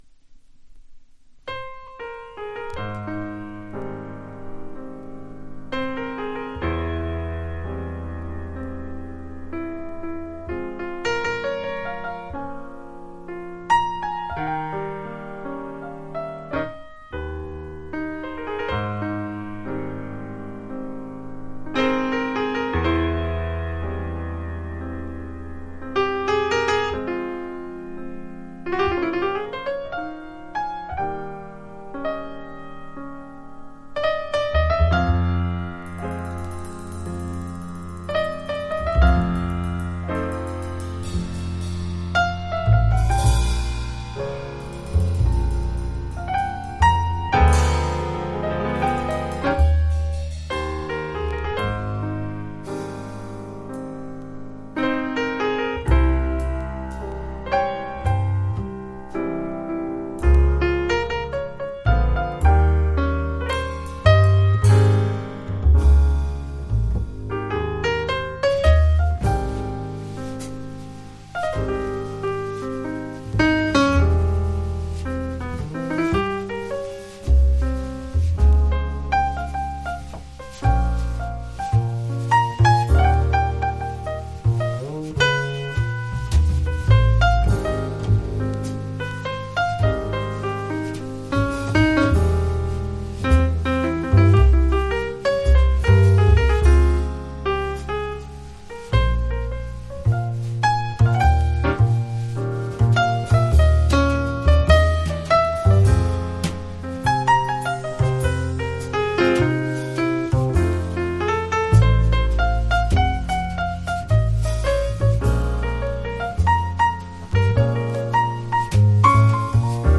（プレス・小傷によりチリ、プチ音ある曲あり）※曲名をクリックすると試聴できます。